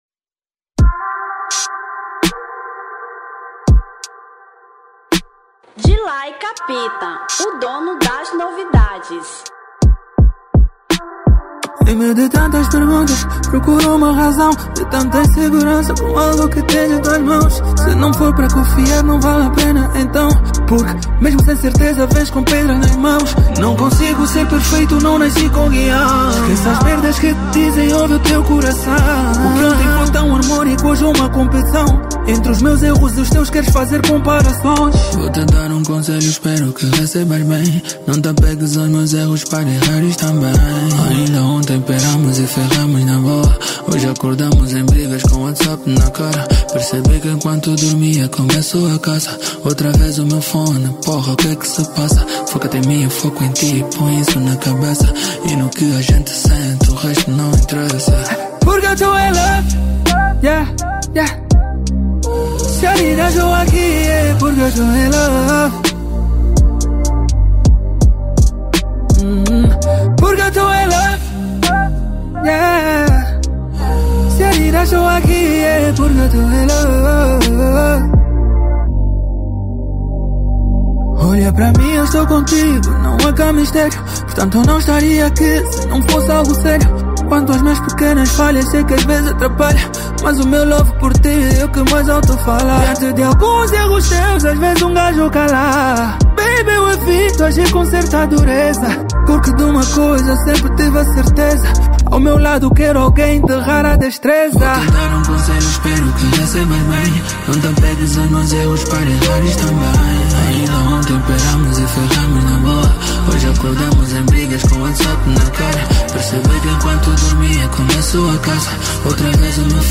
R&B 2025